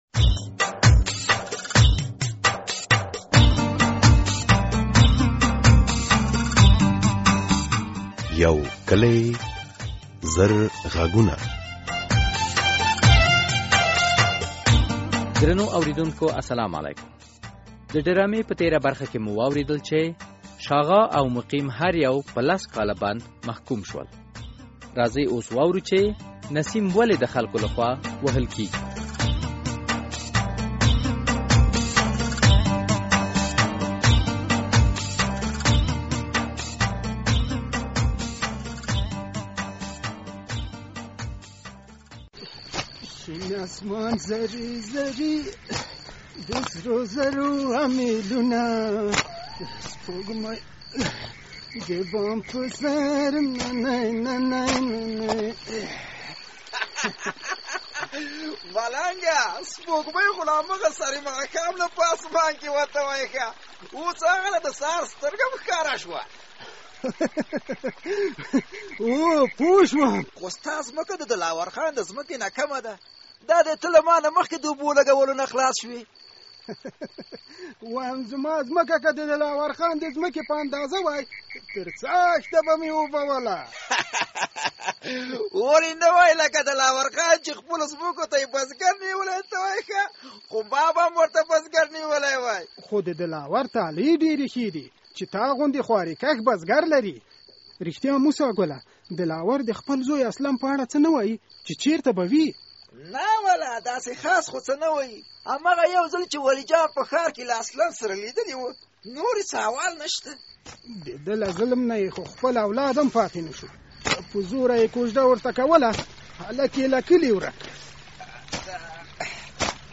د یو کلي زرغږونو ډرامې ۱۰۱مه برخه